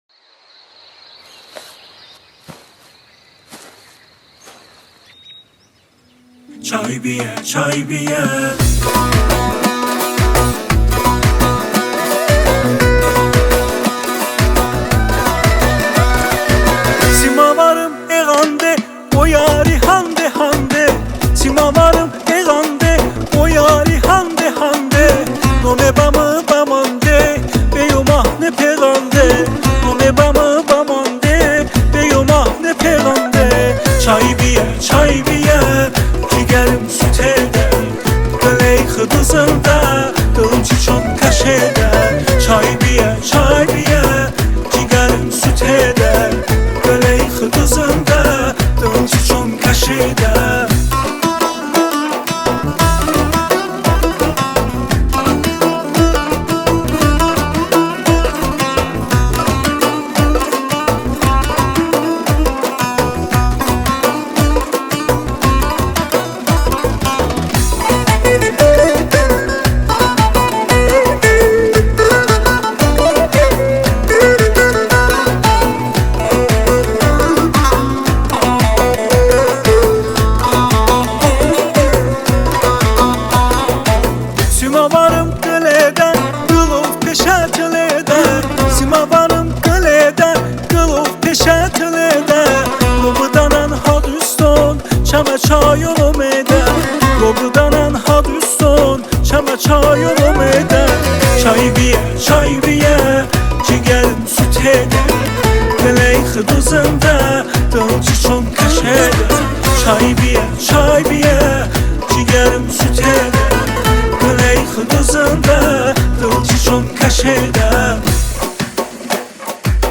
موزیک آذربایجانی